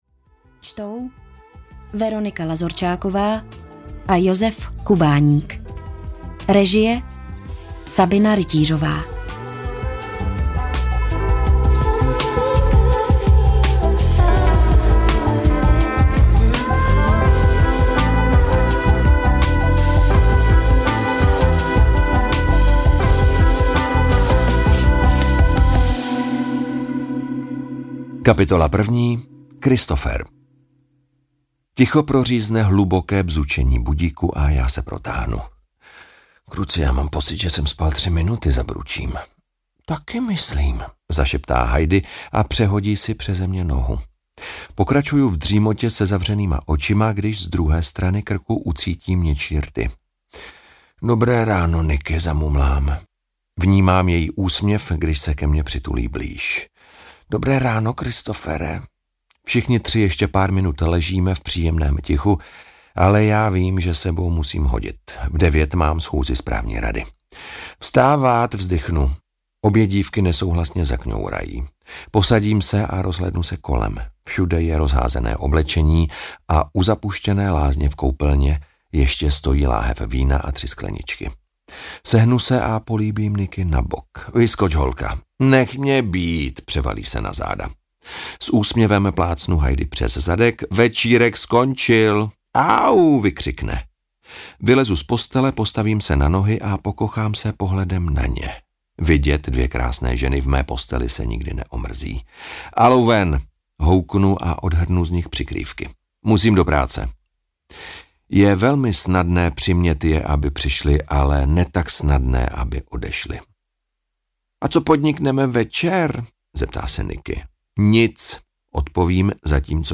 Jen mezi námi audiokniha
Ukázka z knihy